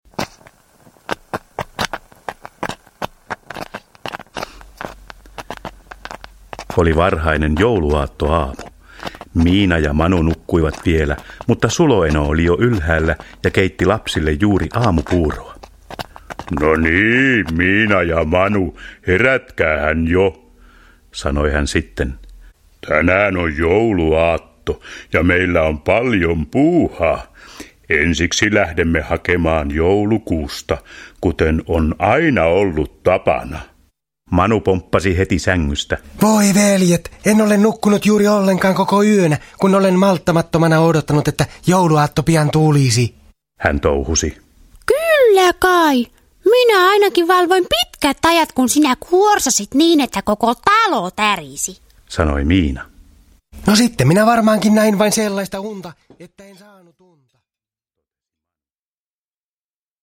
Miinan ja Manun jouluaatto – Ljudbok – Laddas ner